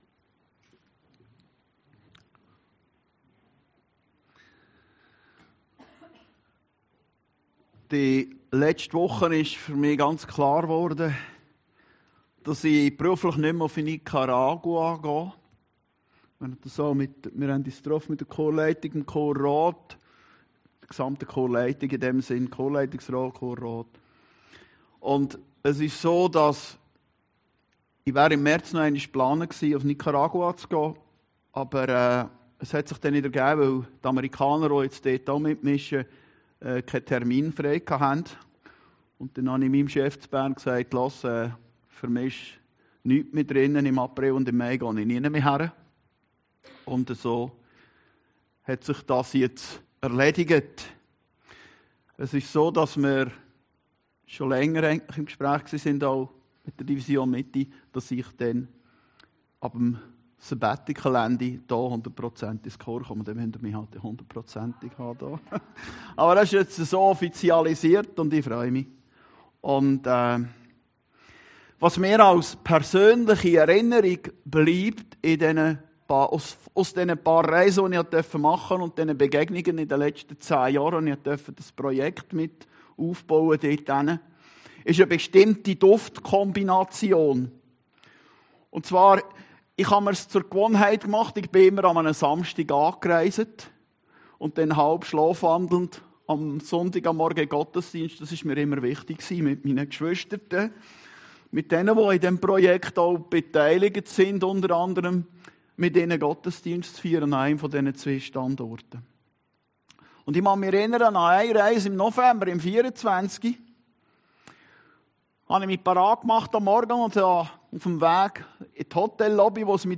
Predigten Heilsarmee Aargau Süd – Die Duftnote des Himmels